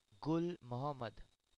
pronunciation, sometimes referred to as Gul Mahomed, (15 October 1921 – 8 May 1992) played Test cricket for India and Pakistan.